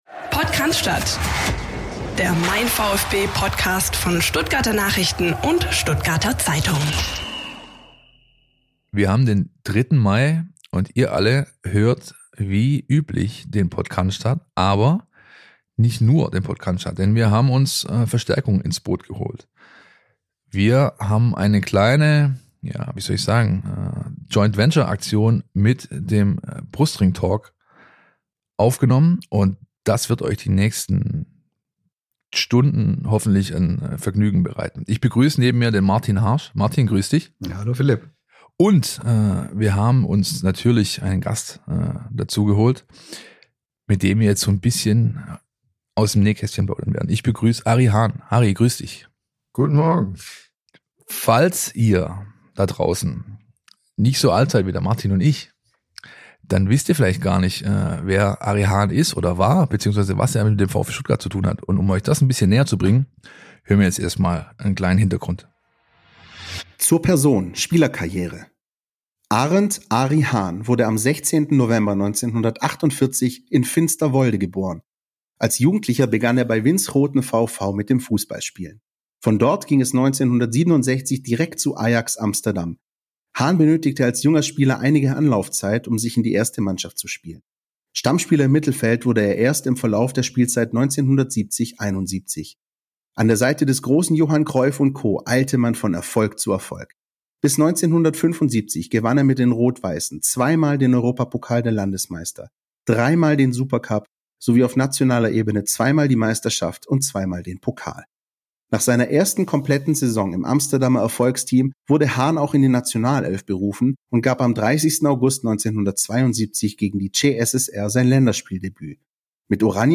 Herausgekommen ist ein mehrstündiges Gespräch über Höhen und Tiefen, Paletten voller Geldscheine in Kamerun, aus der Kabine rausgeworfene Club-Verantwortliche in Hongkong und die Tatsache, dass Haan den VfB jahrelang trainierte – ohne die notwendige Lizenz dafür zu besitzen. Den Höhepunkt des Gesprächs bilden die verrückten 14 Tage im Mai 1989.